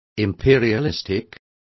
Complete with pronunciation of the translation of imperialistic.